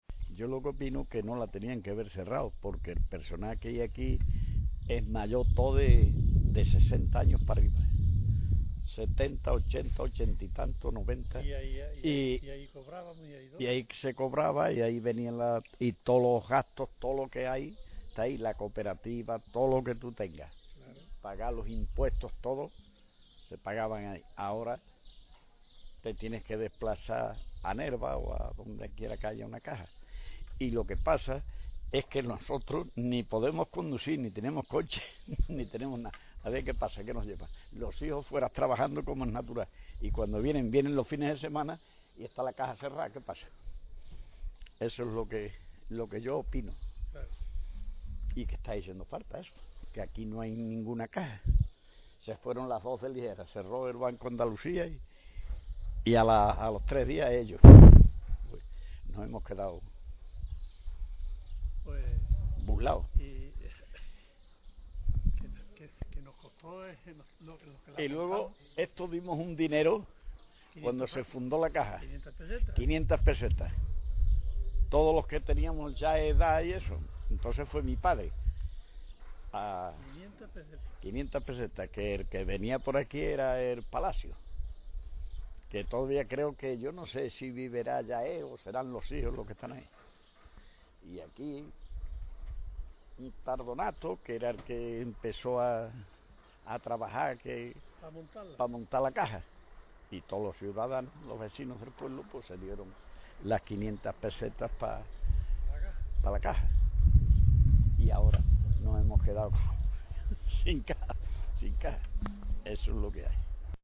Vecino de Berrocal